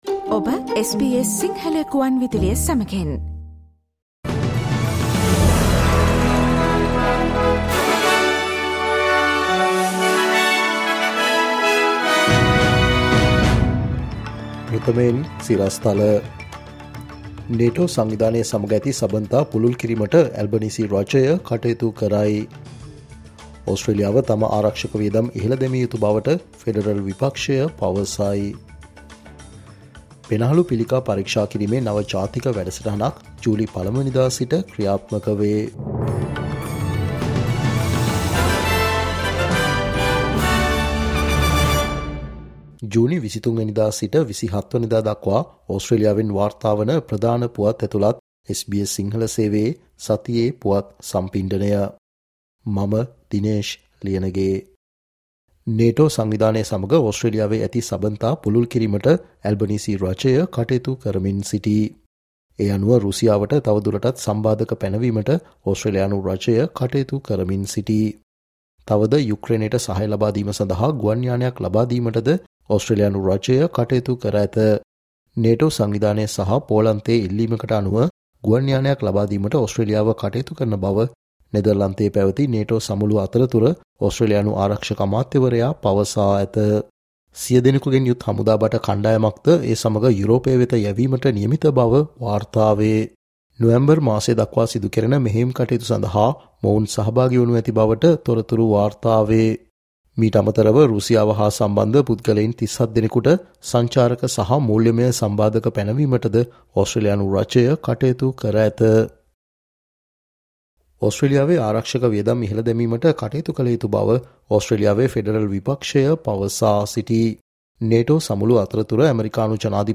ජුනි 23 වනදා සිට ජුනි 27 වනදා දක්වා වන මේ සතියේ ඕස්ට්‍රේලියාවෙන් වාර්තාවන පුවත් ඇතුලත් සතියේ පුවත් ප්‍රකාශයට සවන් දෙන්න